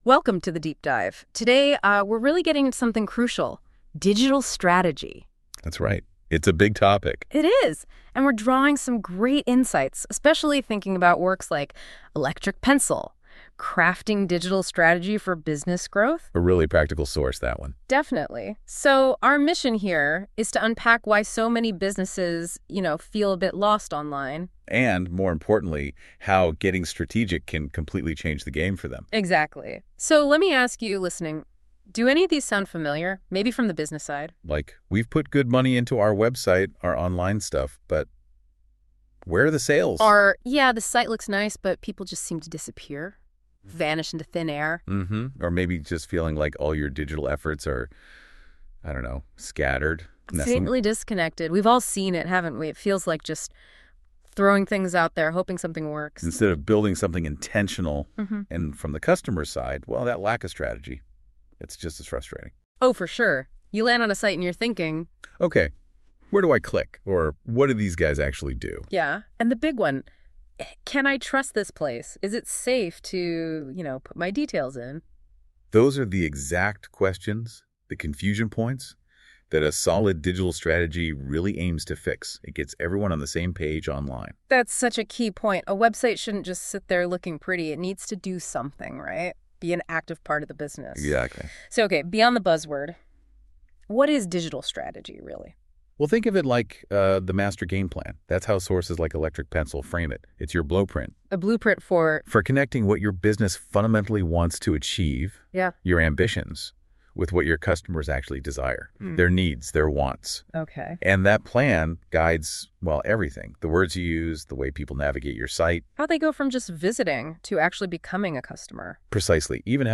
Listen: NotebookLM Deep Dive — “What to Expect From a Digital Consultancy”